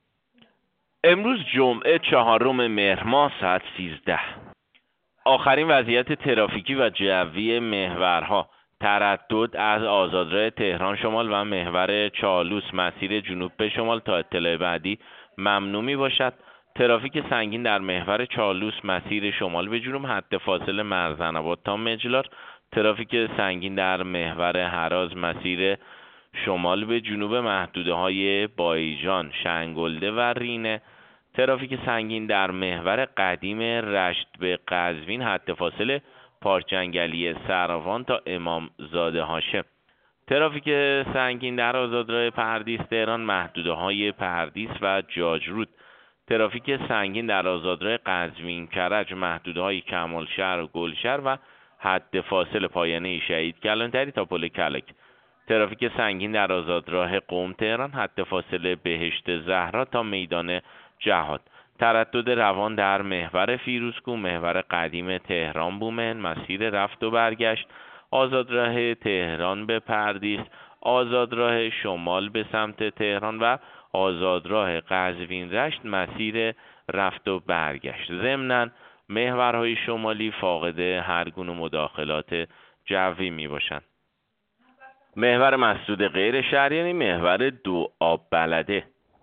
گزارش رادیو اینترنتی از آخرین وضعیت ترافیکی جاده‌ها ساعت ۱۳ چهارم مهر؛